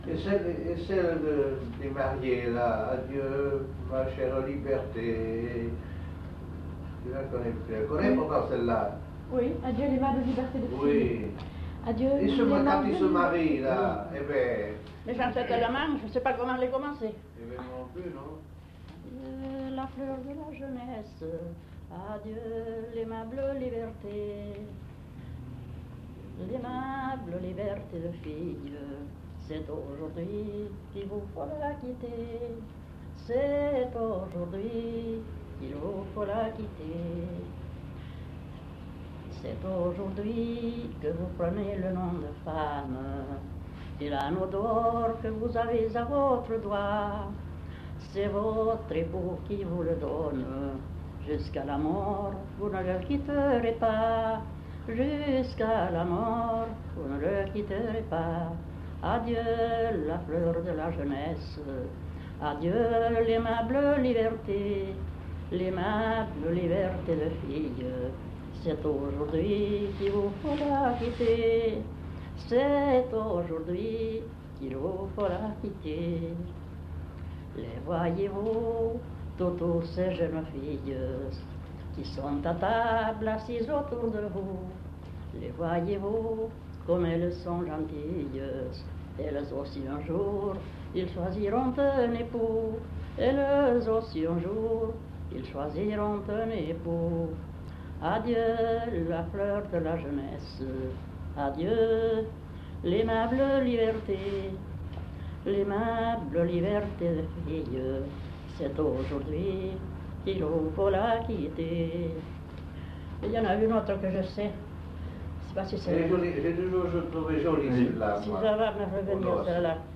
Aire culturelle : Marsan
Lieu : Landes
Genre : chant
Effectif : 1
Type de voix : voix de femme
Production du son : chanté
Notes consultables : L'interprète n'est pas identifiée.